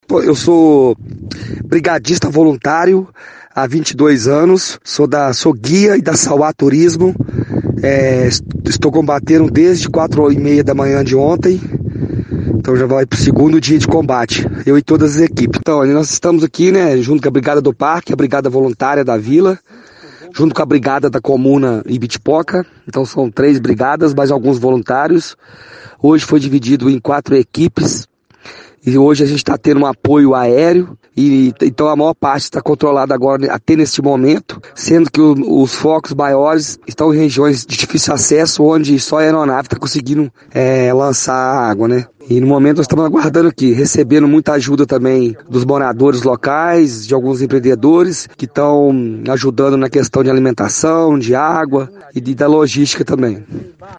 Ibitipoca: Brigadista voluntário relata situação de incêndio no parque
brigadista-parque-estadual-do-ibitipoca.mp3